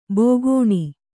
♪ bogōṇi